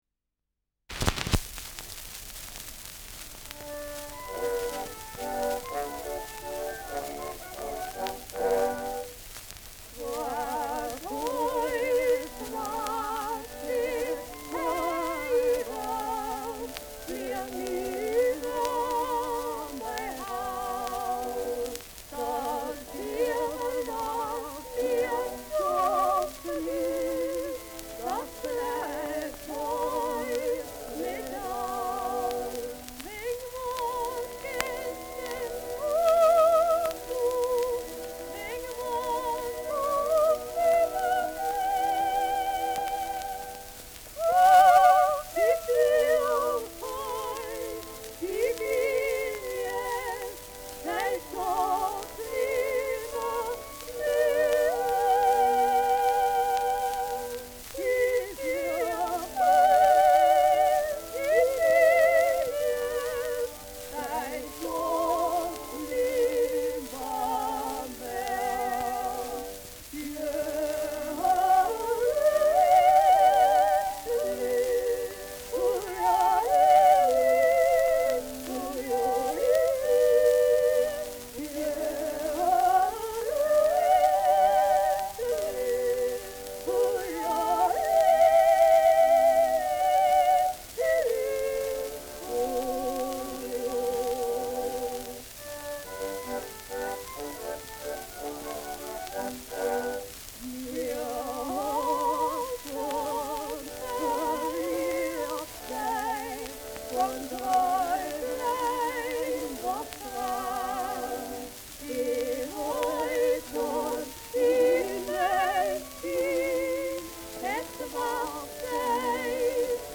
Schellackplatte
Stärkeres Grundrauschen : Gelegentlich leichtes Knacken : Leichtes Leiern
Gaisberg, Frederick William (Ton)
[München] (Aufnahmeort)